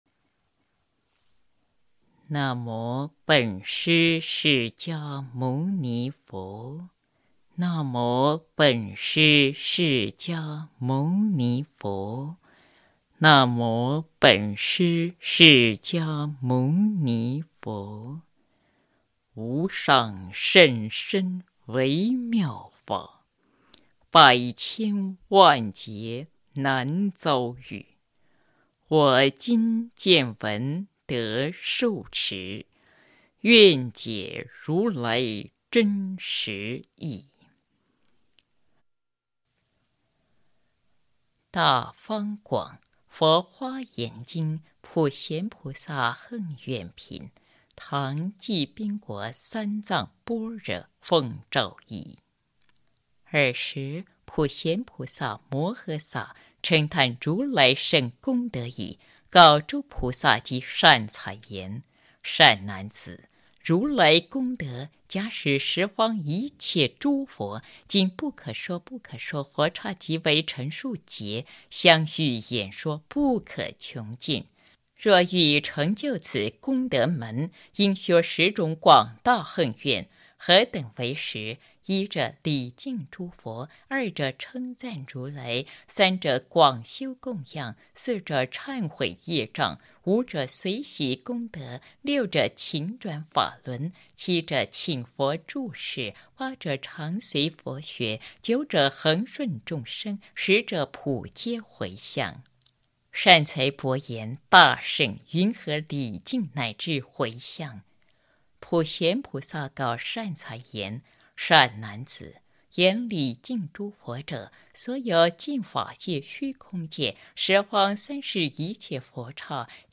普贤菩萨行愿品 - 诵经 - 云佛论坛